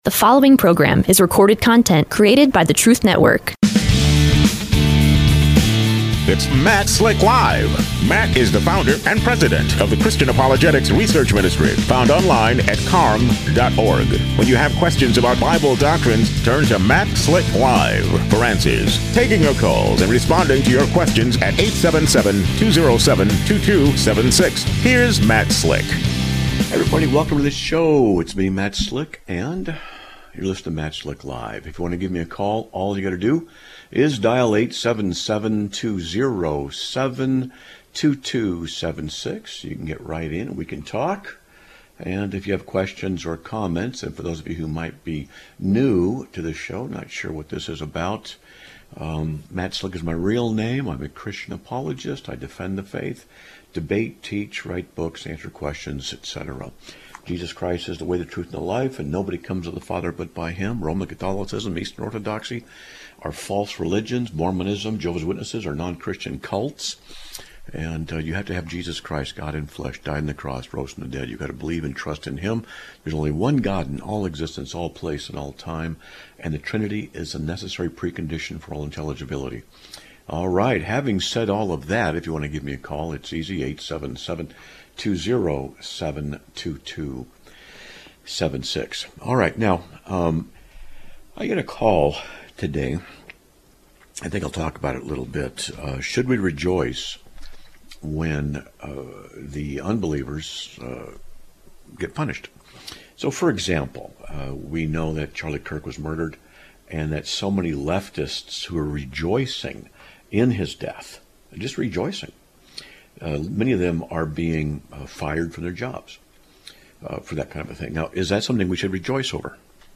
Live Broadcast of 09/23/2025
A Caller Comments on those that Celebrate the Misfortune of Others